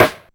Rimshot5.aif